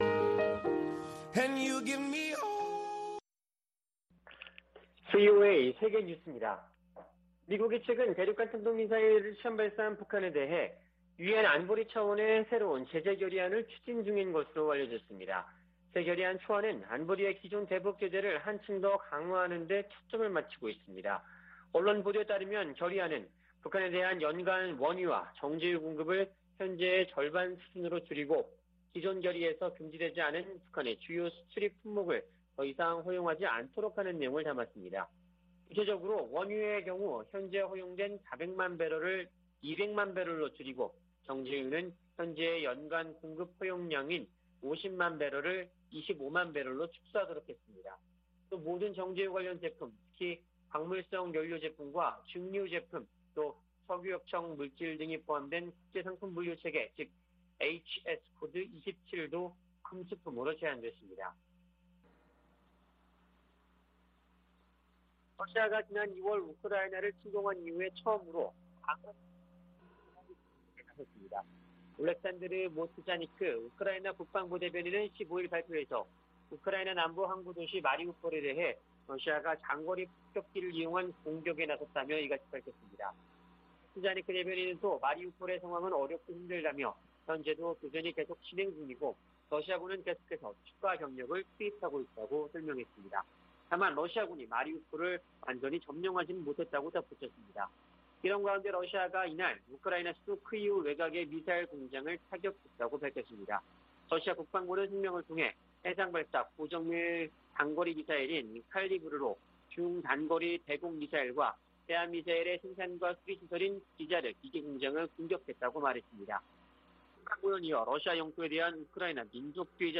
VOA 한국어 아침 뉴스 프로그램 '워싱턴 뉴스 광장' 2022년 4월 16일 방송입니다. 미국의 새 대북 제재 결의안에서 원유와 정제유 공급 상한선을 절반으로 줄이는 등의 내용이 확인됐습니다. 러시아는 한반도 정세를 악화시키는 어떤 조치에도 반대한다며 미국이 추진 중인 새 안보리 대북 결의에 반대 입장을 밝혔습니다. 북한은 아직 미국을 타격할 수 있는 핵탄두 탑재 ICBM 역량을 보유하지 못했다고 백악관 국가안보보좌관이 밝혔습니다.